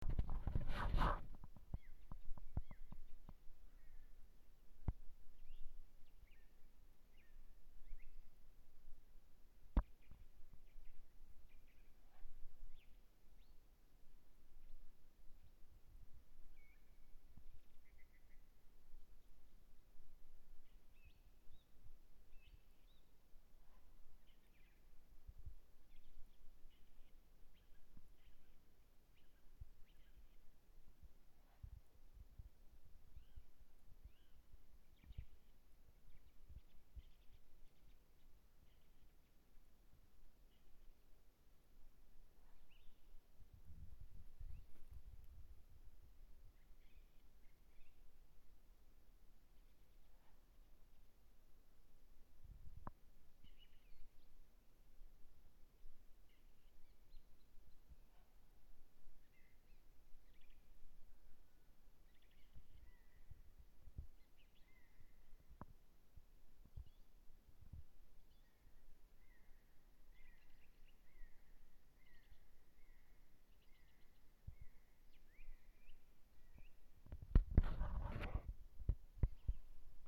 Dziedātājstrazds, Turdus philomelos
Ziņotāja saglabāts vietas nosaukumsBurtnieku novads, Vecdiļļas
StatussDzied ligzdošanai piemērotā biotopā (D)
PiezīmesFonā ļoti klusi saklausāma kāda šalcoša/švīkstoša skaņa. 11,23,30,42,49 un 57.sek. Varbūt nedaudz atgādina skaņu , kāda iztālēm izklausās pļaušana ar izkapti.